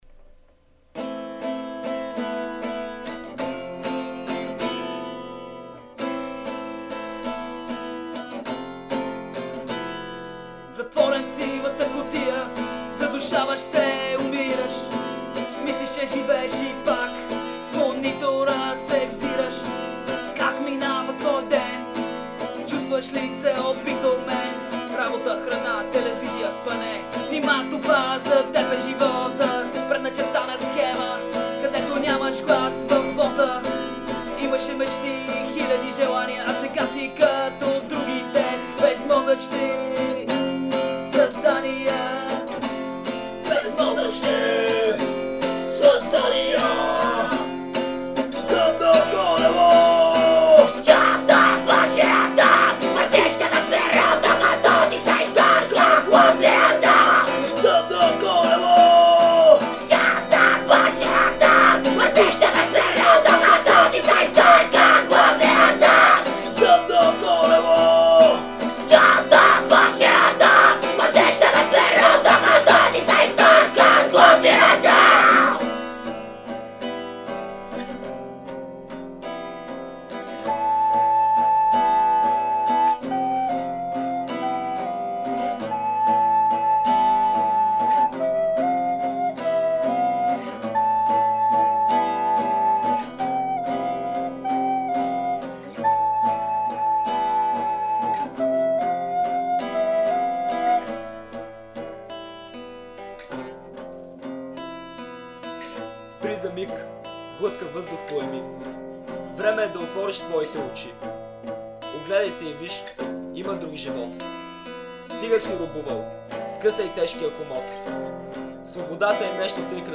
vocal and guitar
flute and back vocals
marakas